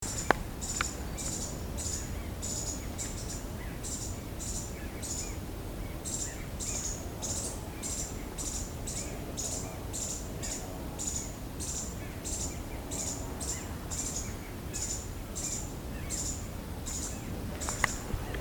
Curutié Pálido (Cranioleuca pallida)
Localidad o área protegida: Serra da Cantareira
Condición: Silvestre
Certeza: Fotografiada, Vocalización Grabada
Pallid-Spinetail.mp3